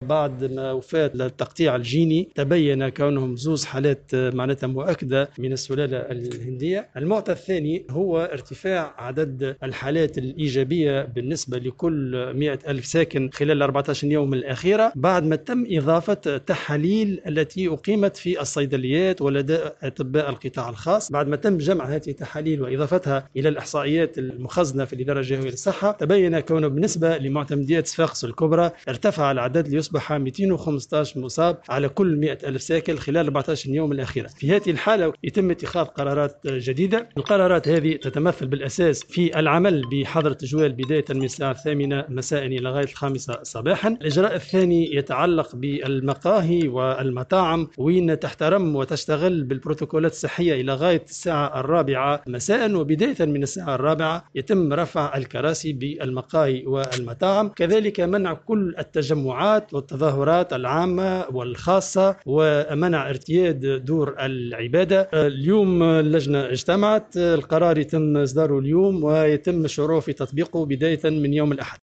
وأضاف في تصريح اليوم لمراسل "الجوهرة أف أم" على هامش اجتماع اللجنة الجهوية لمجابهة الكوارث أنه تم اتخاذ جملة من القرارات، ستدخل حيز التنفيذ بداية من الأحد القادم، من ذلك : * منع التجمعات والتظاهرات العامة والخاصة*رفع الكراسي في المقاهي والمطاعم بداية من الساعة الرابعة مساء* منع ارتياد دور العبادة* مواصلة تطبيق البروتوكولات الصحية في كل القطاعاتمع دراسة مقترحات بتعليق نشاط الأسواق الاسبوعية لمدّة معينة وتطبيق القوانين في الفضاءات العامة (الأسواق اليومية).